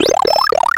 Sound effect of Item Get in Super Mario World (with Power-Up)
SMW_Item_Get_+_Power_Up.oga